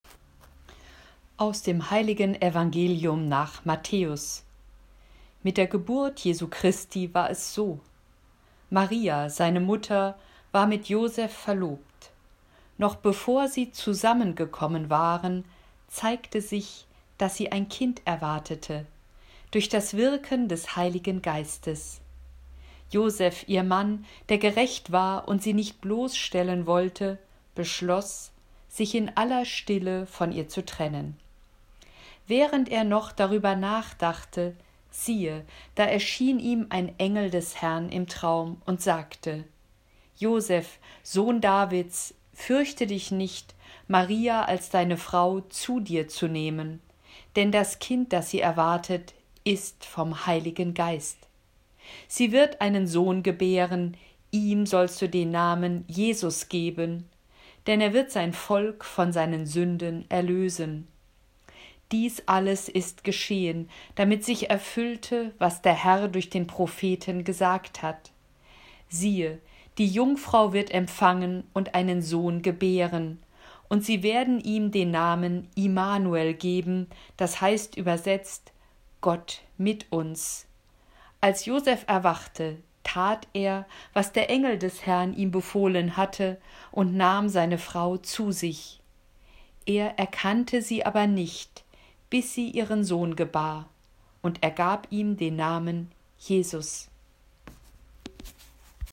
Sprechtraining
Audio-Datei zum Üben der Aussprache von liturgischen Texten